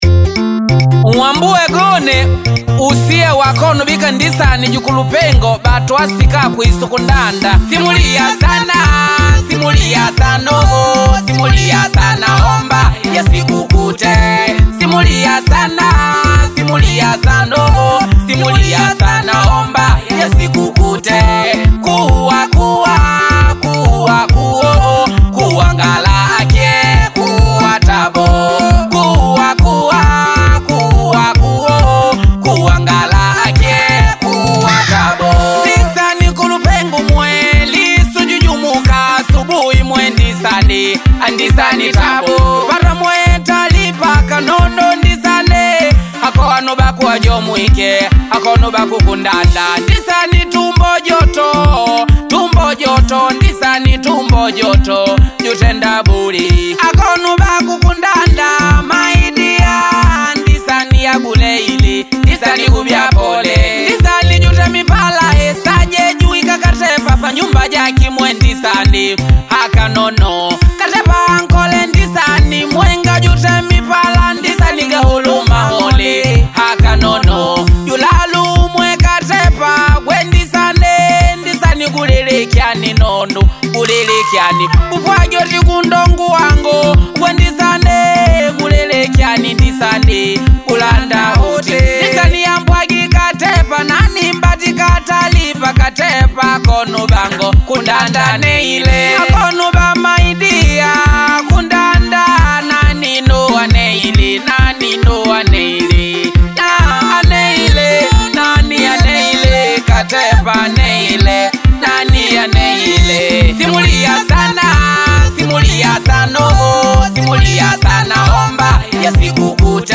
atmospheric soundscapes